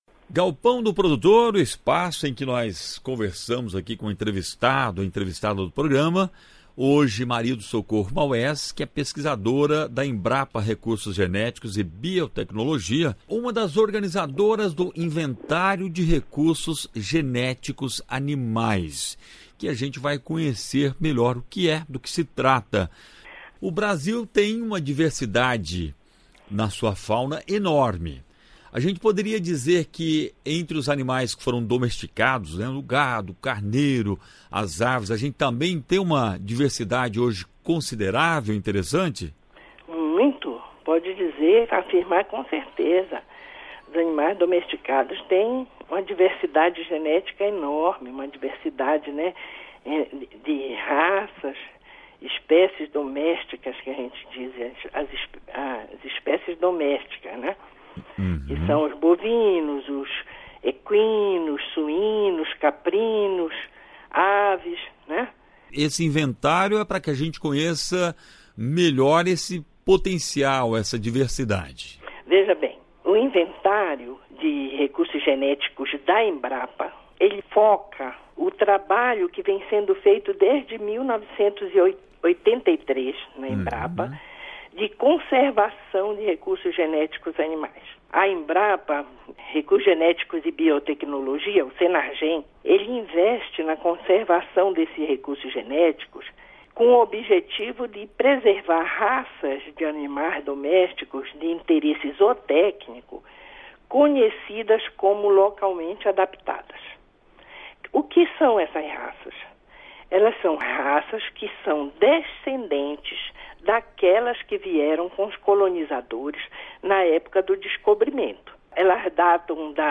Entrevista: Conheça o inventário de recursos genéticos animais da Embrapa